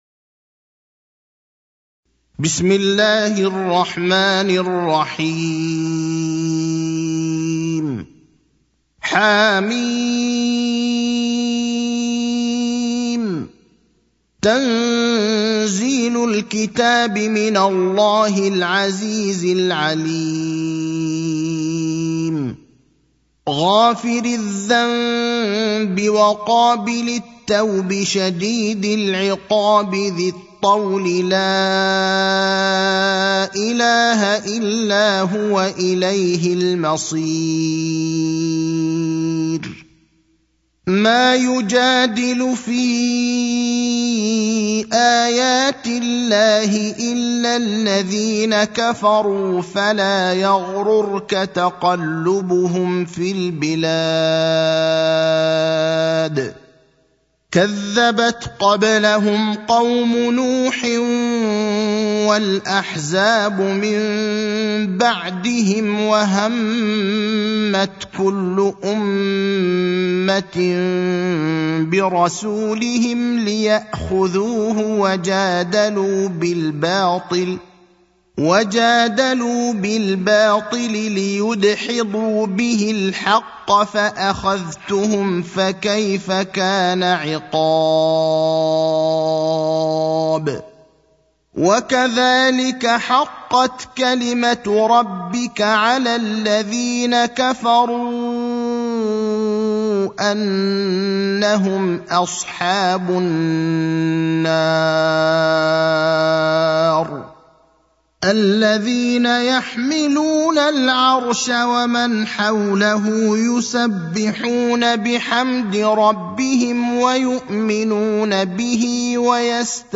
المكان: المسجد النبوي الشيخ: فضيلة الشيخ إبراهيم الأخضر فضيلة الشيخ إبراهيم الأخضر غافر (40)